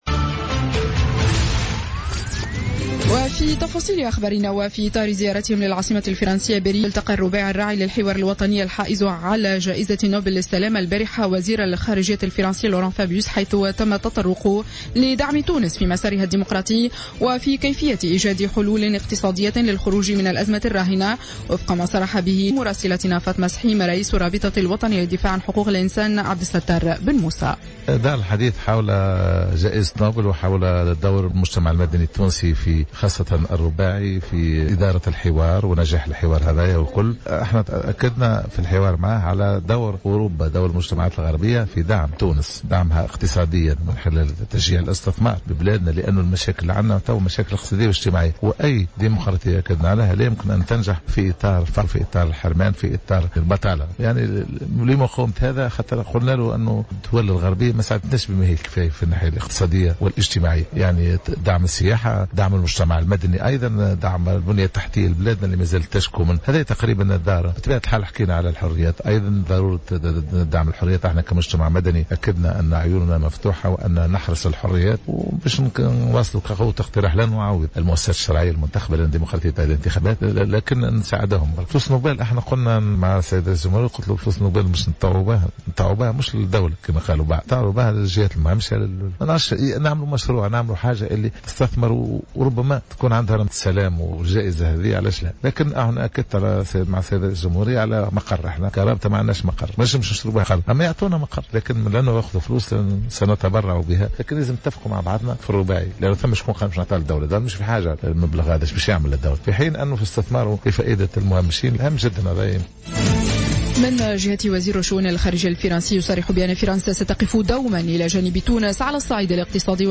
نشرة أخبار السابعة صباحا ليوم الجمعة 16 أكتوبر 2015